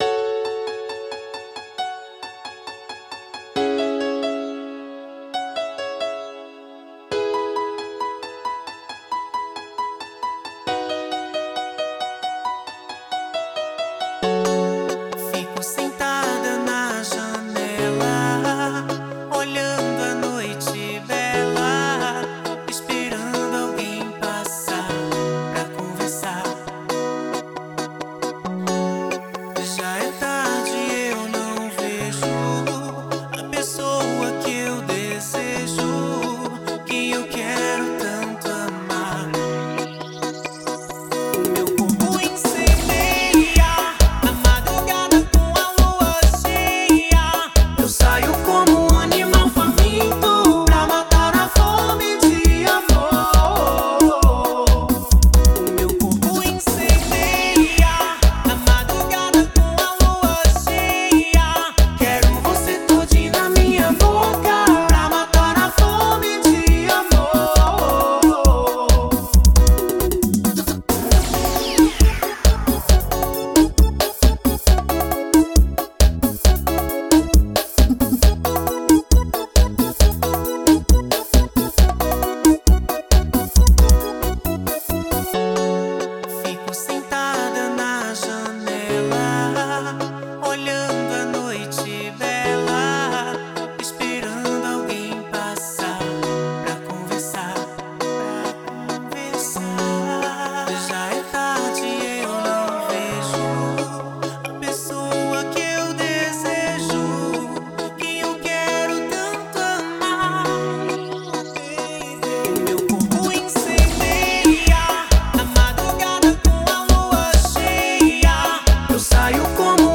A cantora cearense